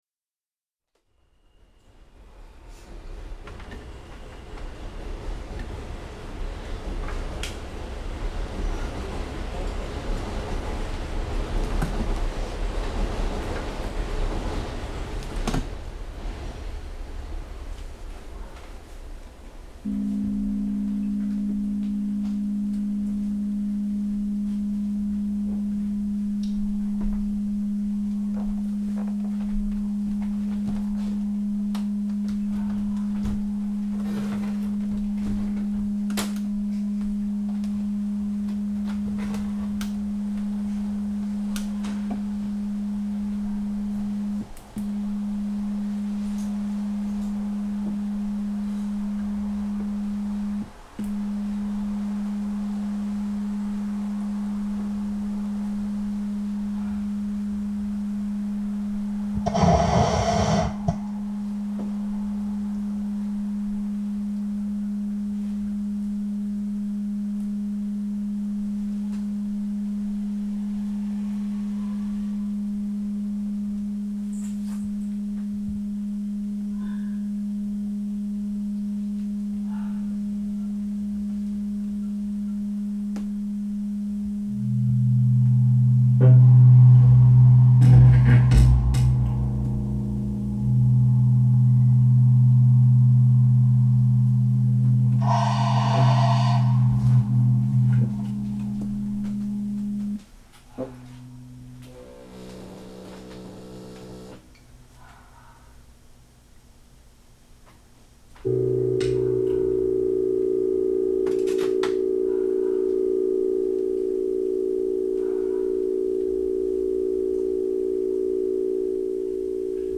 cello, electronics
percussion